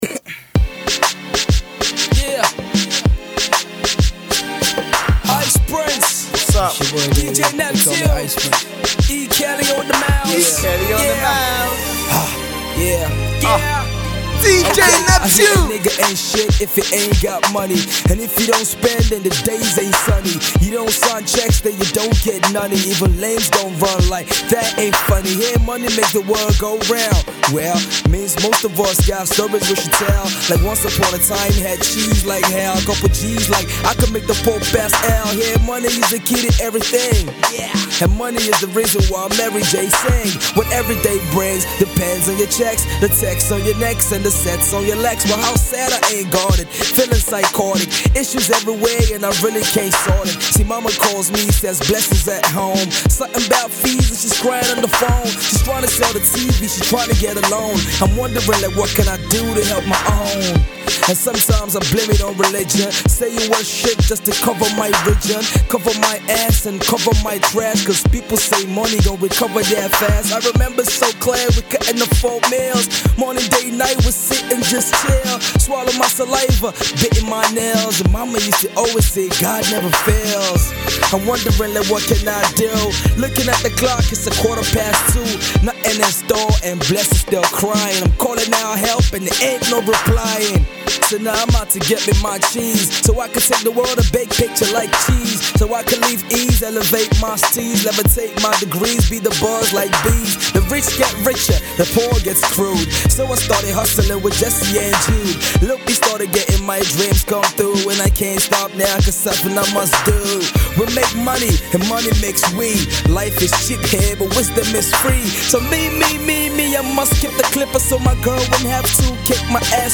He doesn’t need a hook and he drops hot bars.